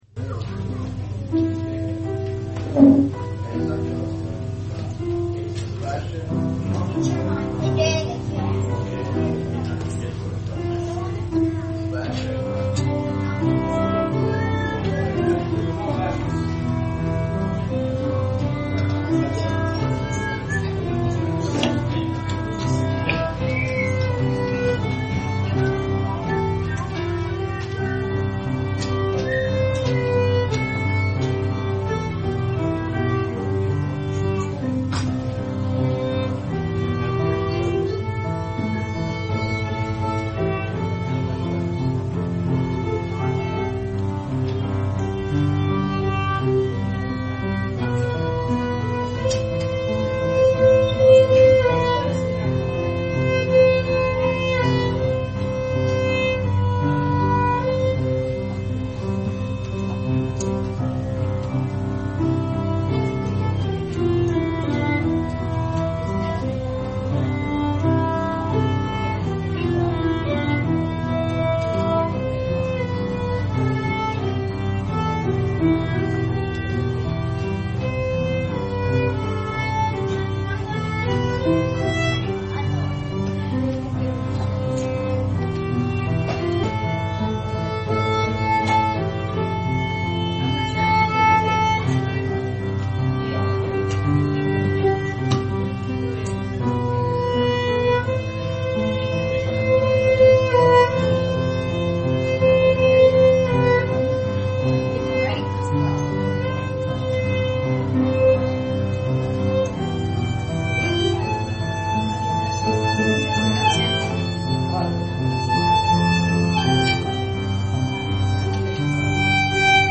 teaches on the mercy of judgement of an unrepentant sinner